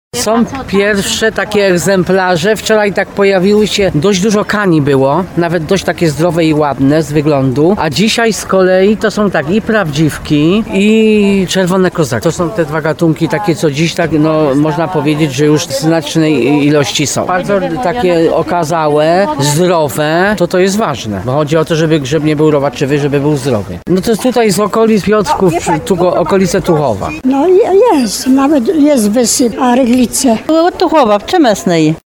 Ludzie wracają z pełnymi koszami — mówi jedna z handlujących na popularnym tarnowskim placu targowym.